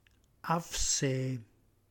Qui di seguito il nostro repertorio delle parole “reggiane” proprie del nostro dialetto, sia per vocabolo che per significato ad esso attribuito, corredate della traccia audio con la dizione dialettale corretta.